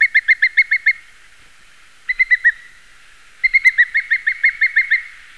Booted eagle
Booted-eagle.mp3